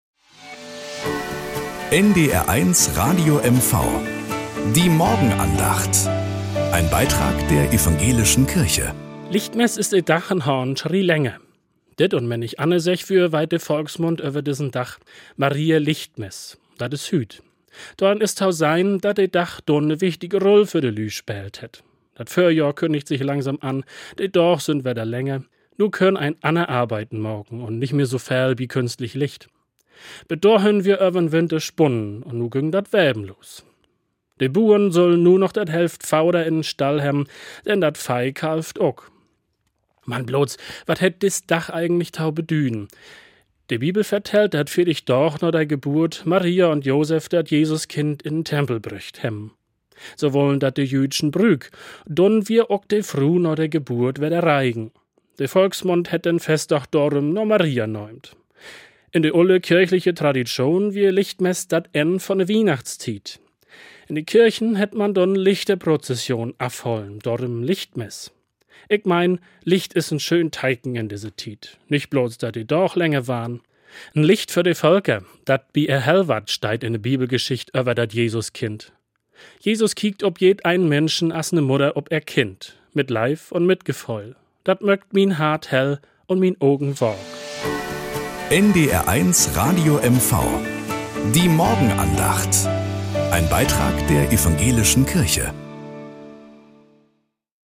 Morgenandacht auf NDR 1 Radio MV
Um 6:20 Uhr gibt es in der Sendung "Der Frühstücksclub" eine